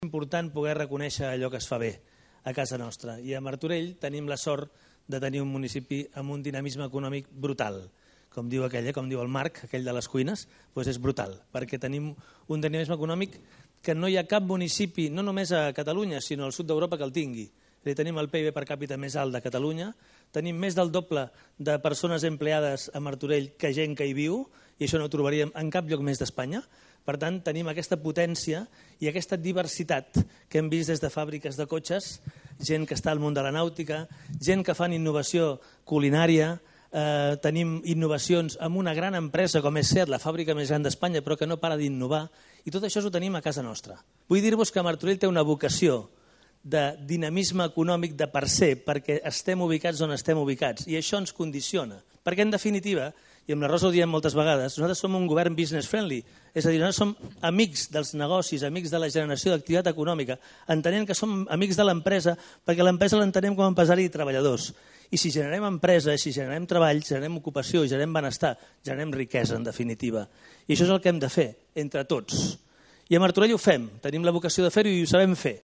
El Centre de Promoció Econòmica Molí Fariner ha acollit aquest divendres la 1a edició de ‘Martorell Reconeix: Empresa’, un acte impulsat per l’Ajuntament de Martorell per reconèixer públicament la trajectòria, l’esforç i la contribució del teixit empresarial del municipi.
Xavier Fonollosa, alcalde de Martorell
Martorell-Reconeix-01.-Xavier-Fonollosa.mp3